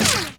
player_dodge_success.wav